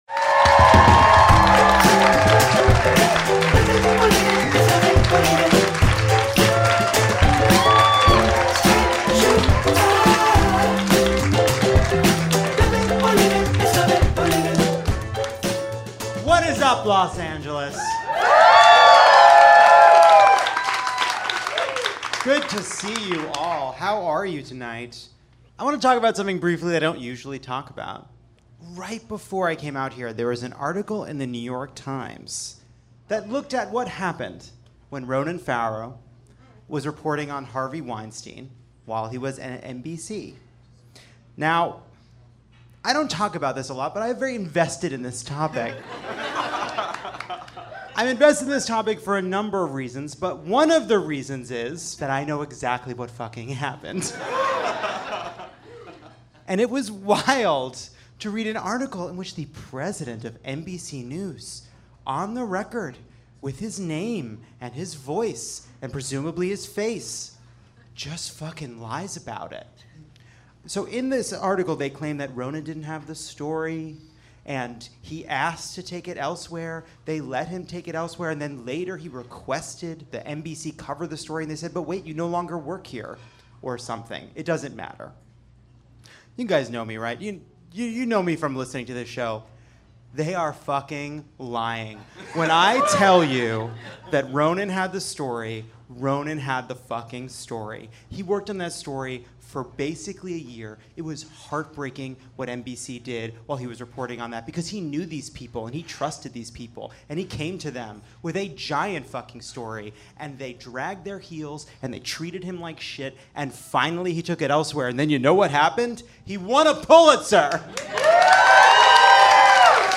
Mandy Moore and Dawes's Taylor Goldsmith join for a very special dramatic reading of Kellyanne and George Conway's public disagreements.